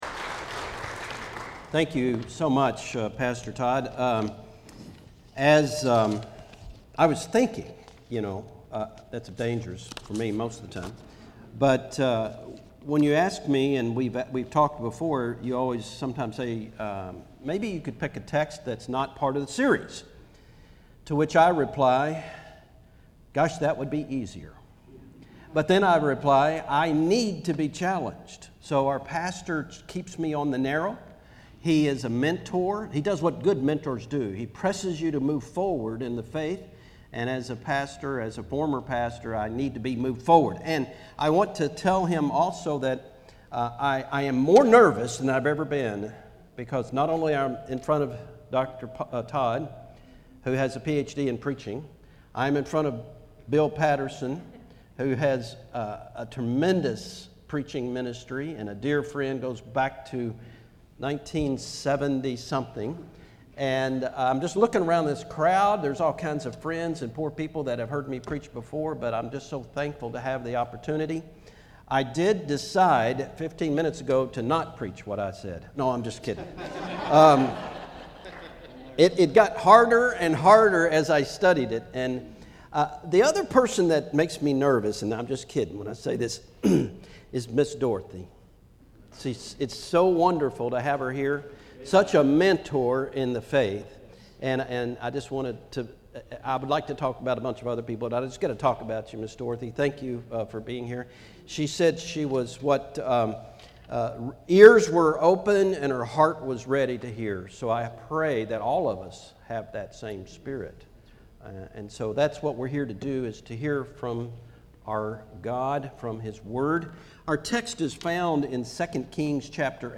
2016 The Good The Bad The Ugly 2 Kings This is an evening sermon with no manuscript attached.